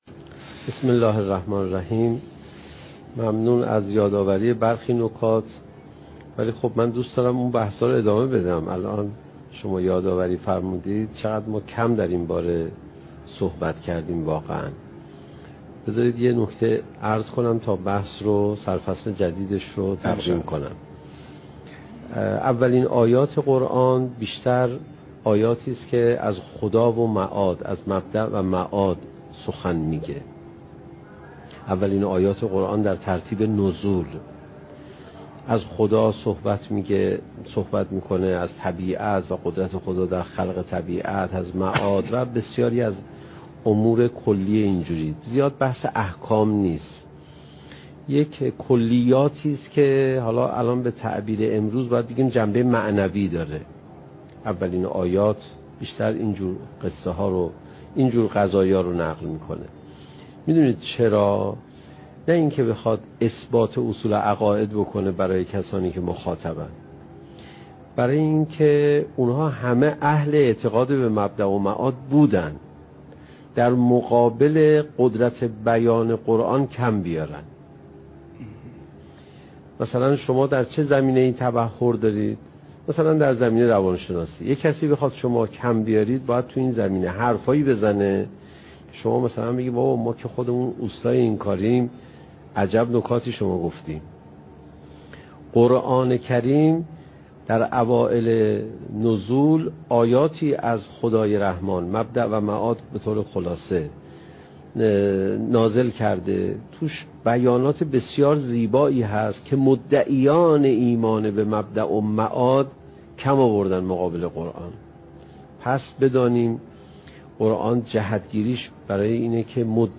سخنرانی حجت الاسلام علیرضا پناهیان با موضوع "چگونه بهتر قرآن بخوانیم؟"؛ جلسه سیزدهم: "نابودی کبر، مقدمه تفکر"